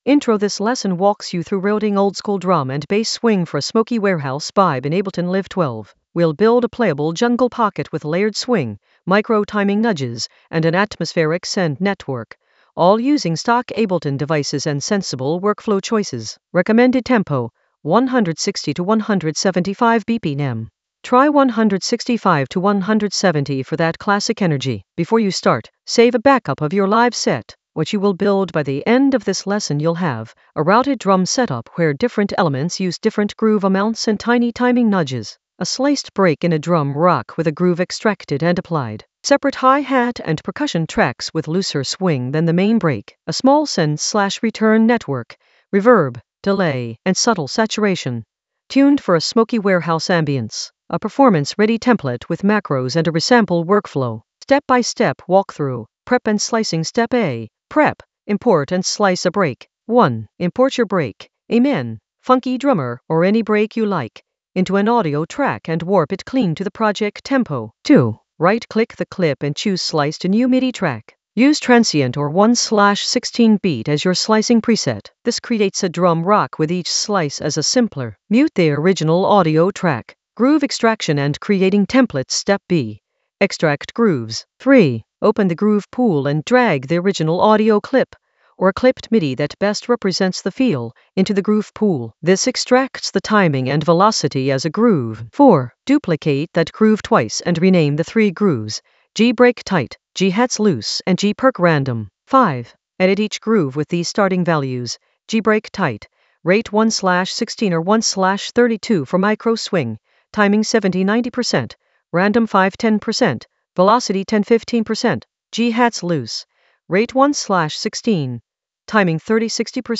An AI-generated intermediate Ableton lesson focused on Route oldskool DnB swing for smoky warehouse vibes in Ableton Live 12 for jungle oldskool DnB vibes in the Workflow area of drum and bass production.
Narrated lesson audio
The voice track includes the tutorial plus extra teacher commentary.